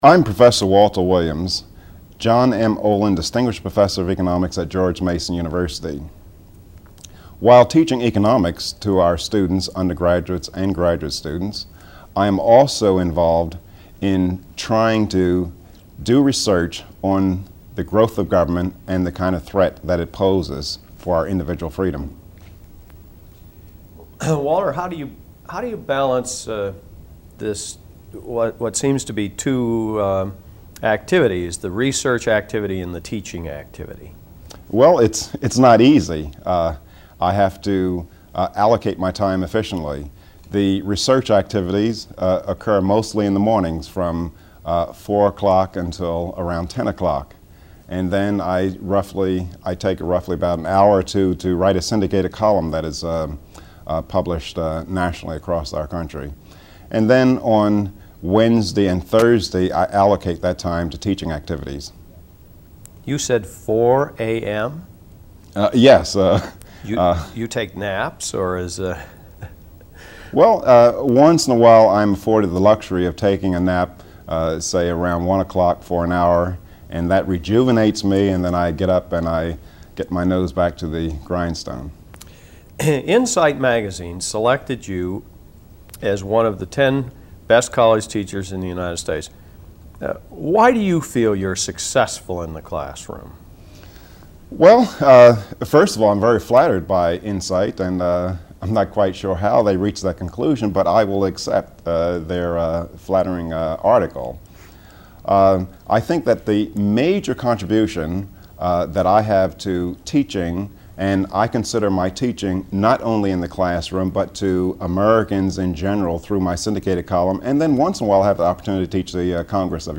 Listen to the full interview in the latest episode of The Free To Choose Media Podcast to find out.